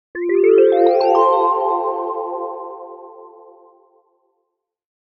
Modern Futuristic Notification Sound Effect
This modern futuristic notification sound has a soft, calm digital tone. It works great for apps, smartphones, UI design, and sci-fi content.
Modern-futuristic-notification-sound-effect.mp3